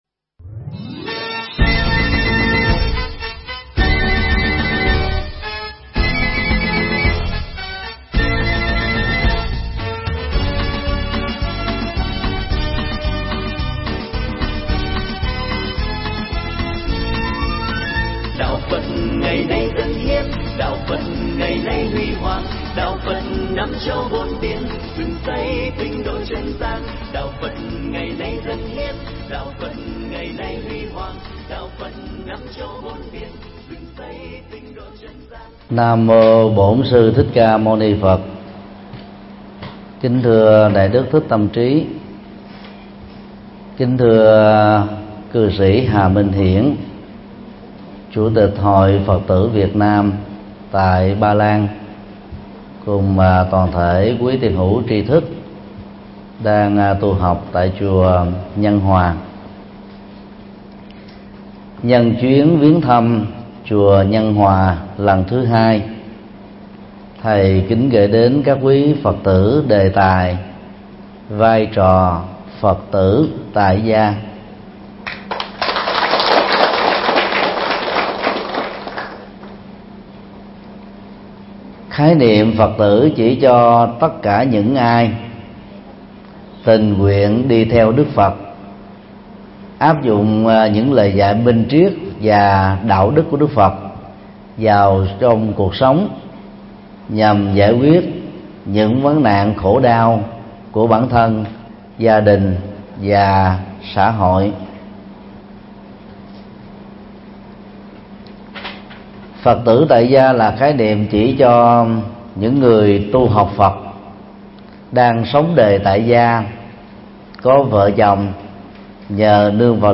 Mp3 Thuyết Pháp Vai Trò Phật Tử Tại Gia - Thầy Thích Nhật Từ Giảng tại chùa Nhân Hòa, Warszawa- Ba Lan, ngày 26 tháng 7 năm 2014